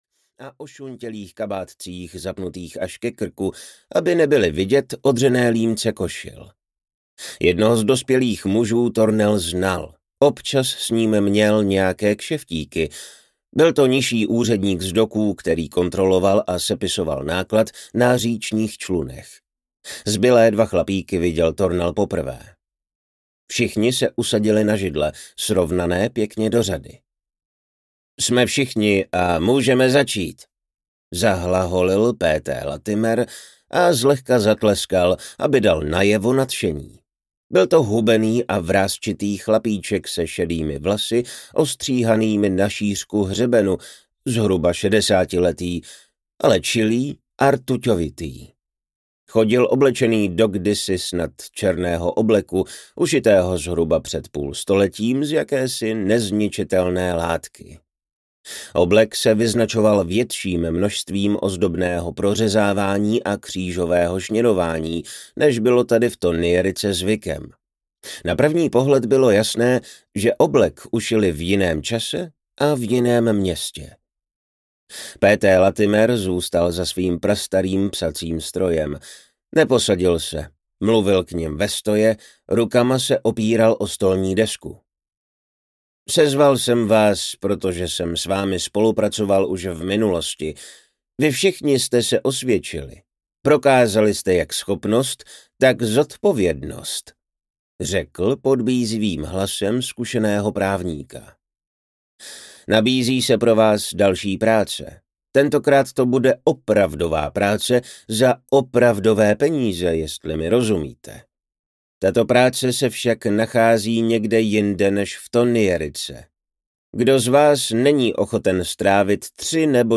Zima v Thonnierice audiokniha
Ukázka z knihy